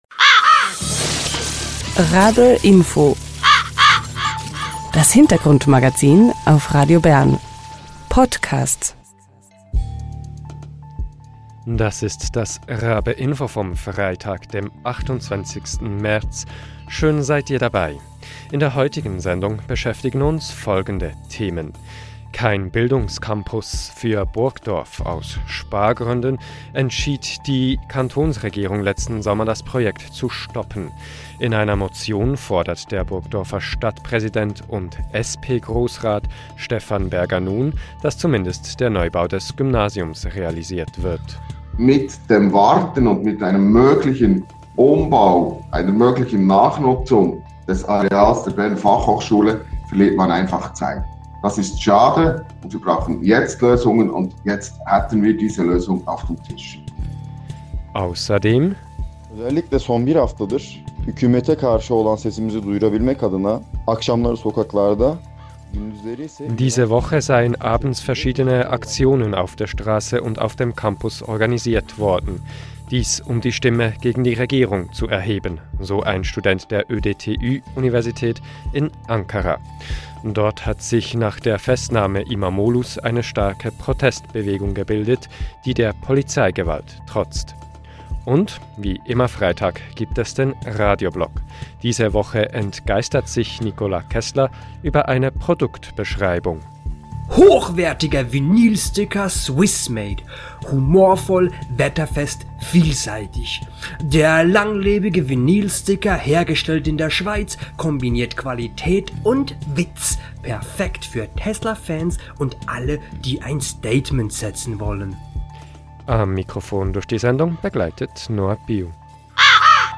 An der rennommierten Technischen Universität des Nahen Ostens (ODTÜ) in Ankara hat sich in dieser Woche eine starke Protest- und Solidaritätsbewegung gebildet. Ein Studierender der mit dabei ist erzählt von den Protestaktionen.